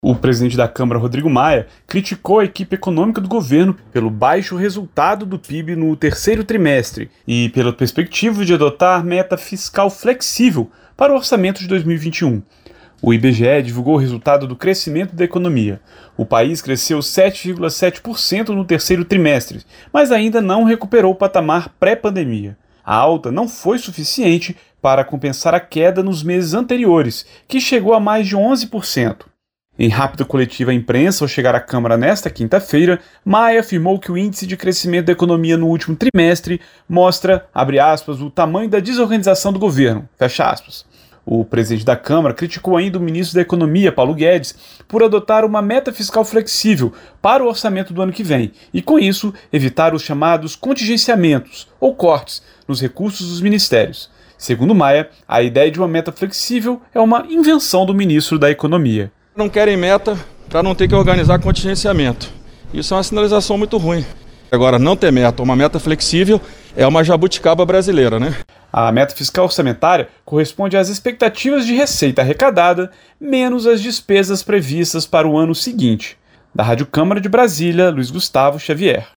Esse resultado mostra “o tamanho da desorganização do governo”, afirmou Maia numa rápida coletiva concedida aos jornalistas na manhã desta quinta-feira.